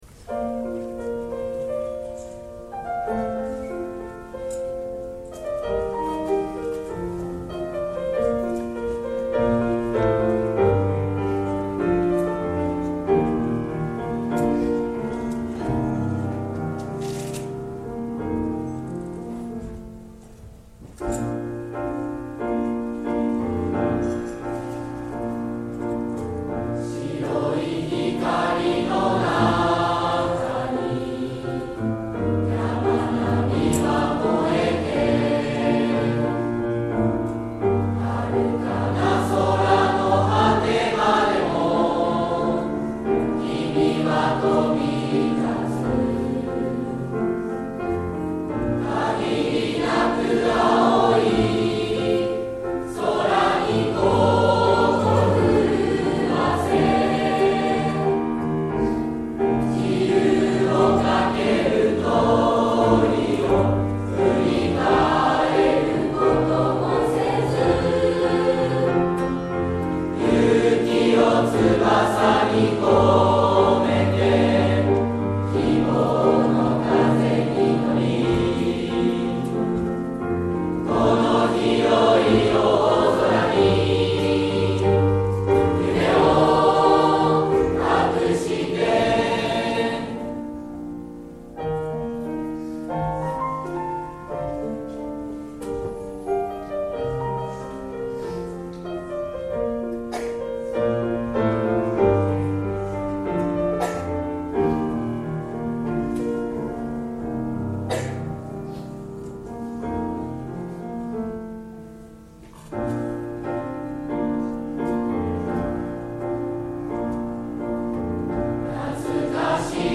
平成28年3月11日(金)　第53回卒業証書授与式
その決意も込められた本日の卒業生の歌声をどうぞ！
53期生　卒業の歌「旅立ちの日に」